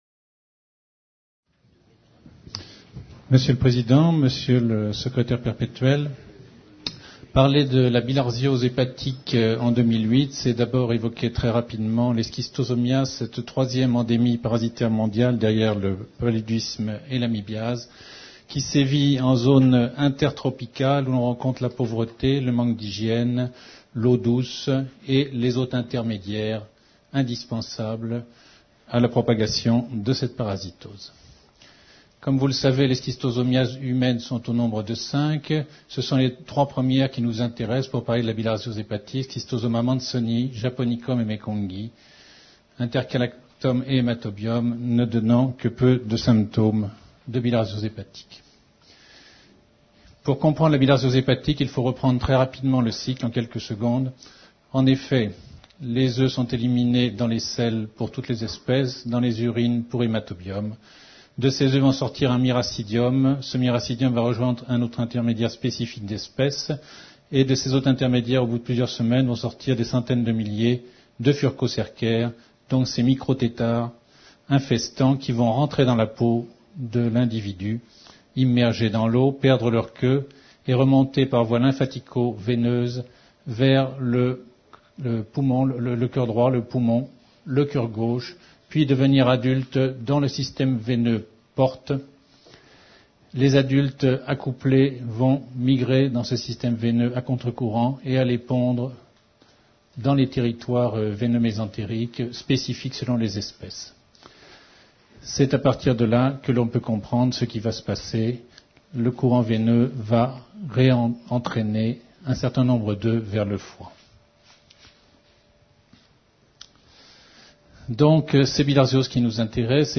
La conférence a été donnée à l'Université Victor Segalen Bordeaux 2 à l’occasion de la séance délocalisée de l’Académie Nationale de Médecine « Bordeaux, Porte Océane » le 15 avril 2008.